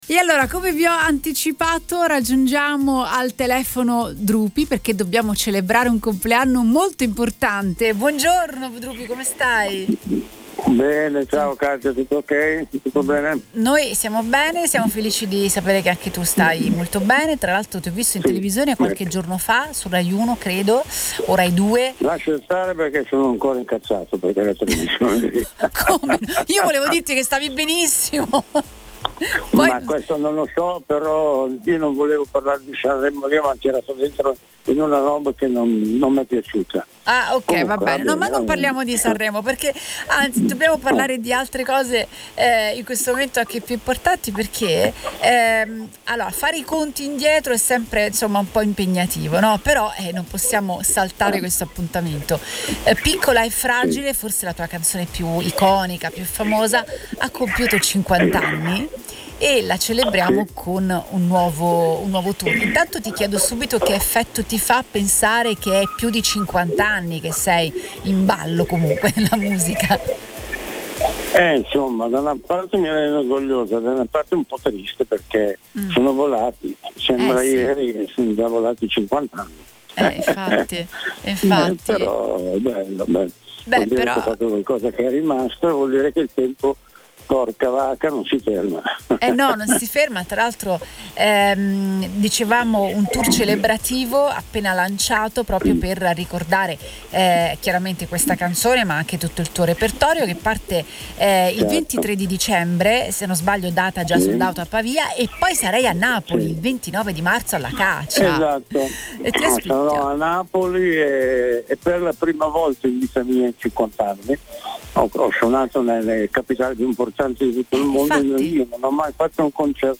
Napoli – Il cantautore Drupi è tornato al telefono con Radio Punto Nuovo per celebrare un anniversario d’oro: i 50 anni dall’uscita del suo brano più iconico, “Piccola e fragile”. L’occasione è stata anche il lancio di un nuovo tour celebrativo che, per la prima volta in cinquant’anni di carriera, toccherà anche Napoli.